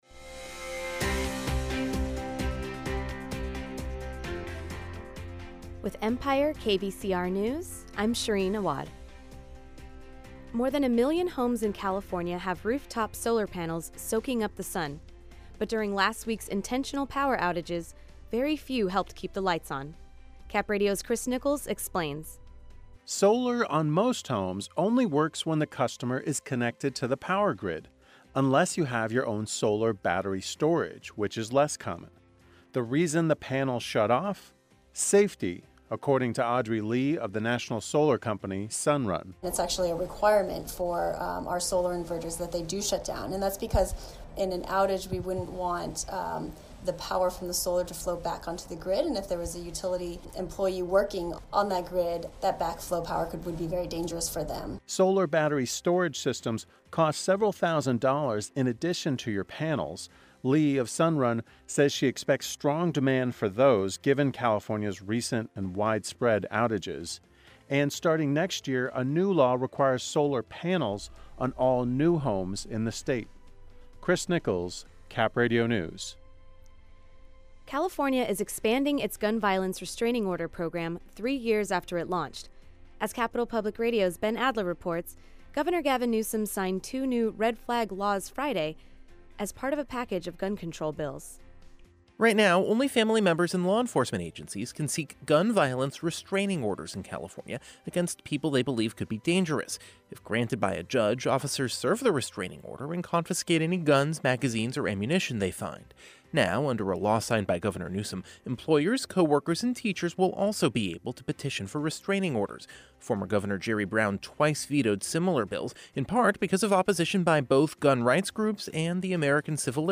KVCR News has your daily news rundown at lunchtime.